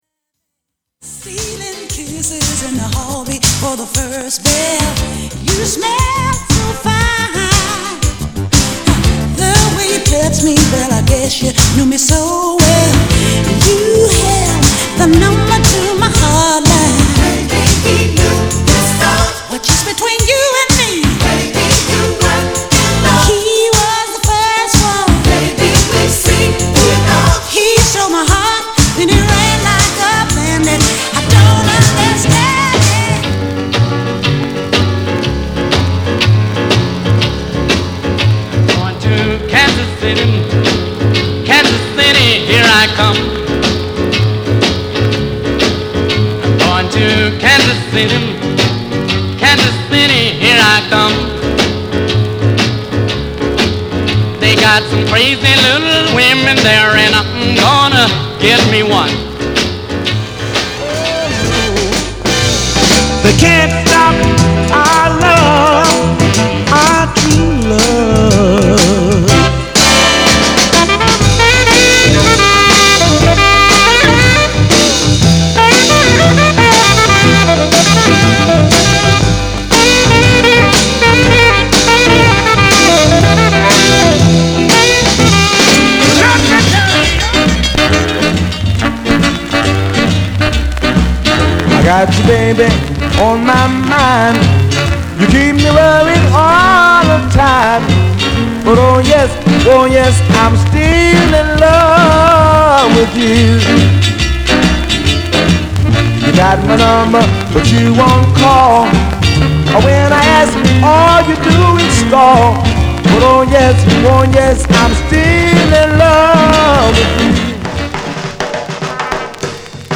/盤質/両面やや傷あり再生良好です/US PRESS